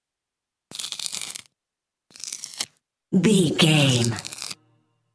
Creature eating flesh peel short
Sound Effects
scary
eerie
horror